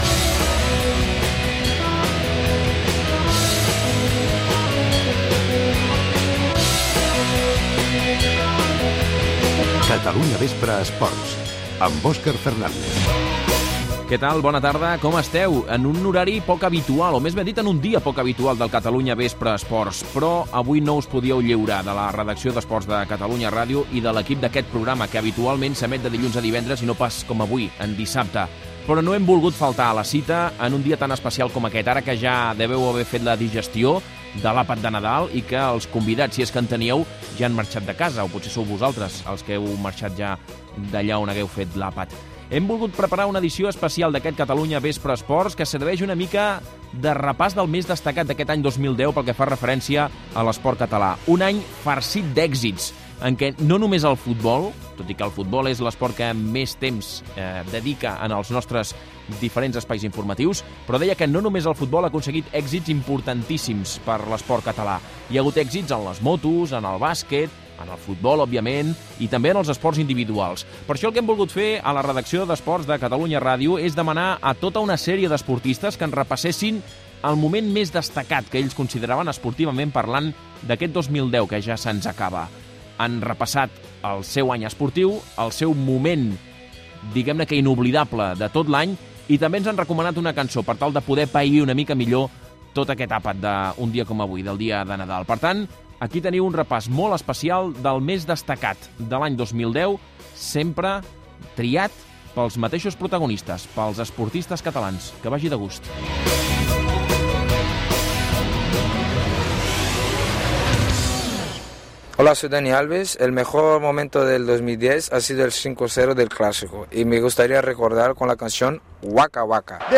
Indicatiu del programa, edició especial de Nadal, els esportistes recorden el millor moment de l'any i demanen un tema musical: Dani Alves, Grimau, Joan Verdú, Roger Grimau
Esportiu